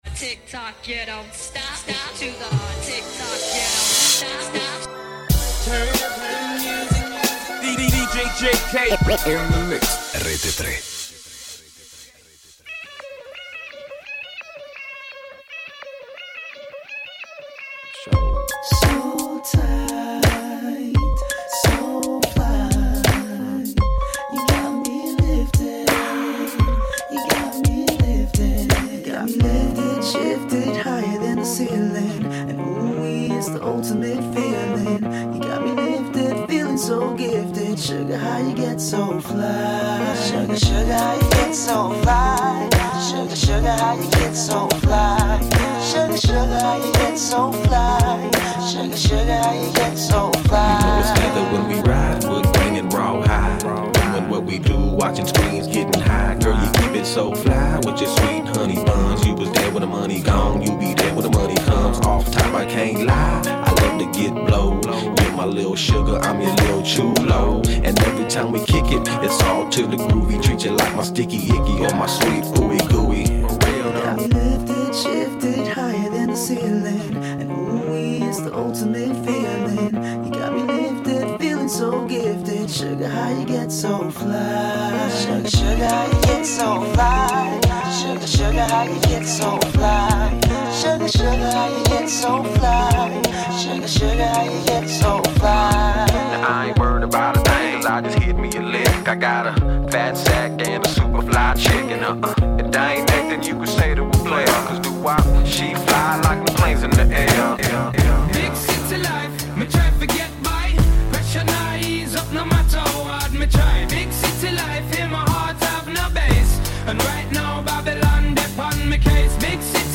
RAP / HIP-HOP BLACK